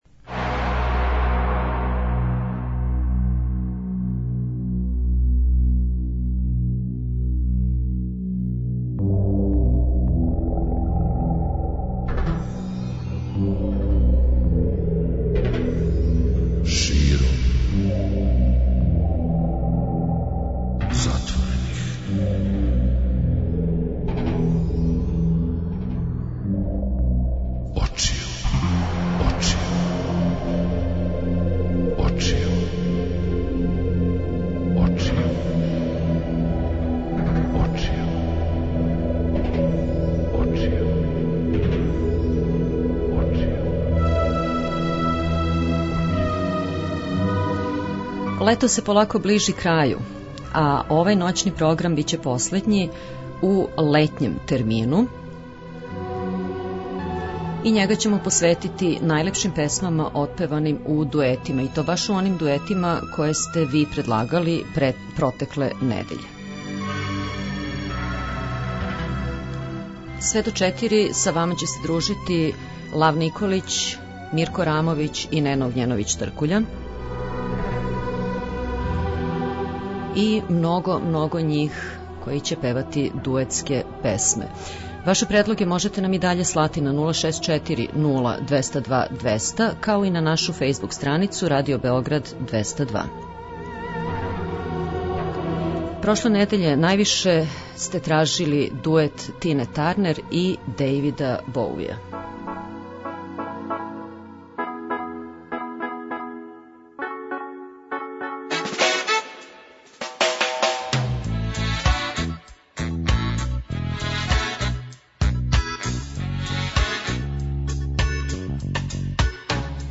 Још један ноћни програм у лаганом летњем ритму посветићемо најлепшим песмама отпеваним у дуетима, које сте ви предложили претходне недеље.